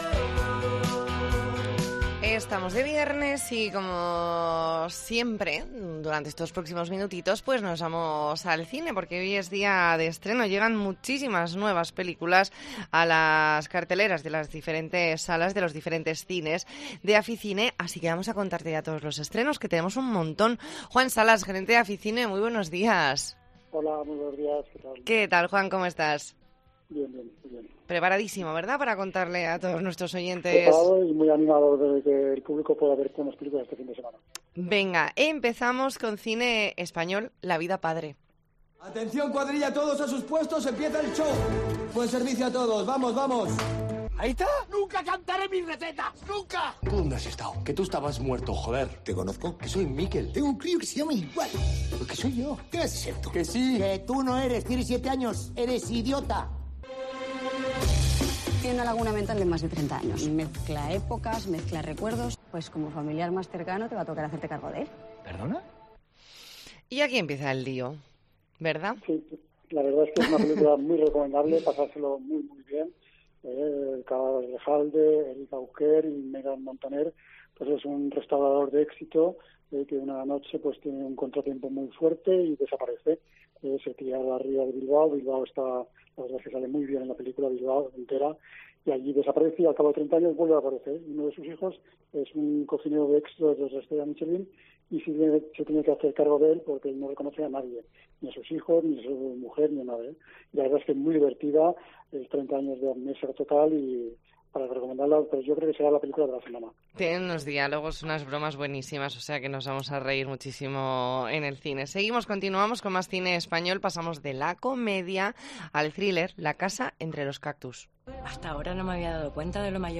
Entrevista en La Mañana en COPE Más Mallorca.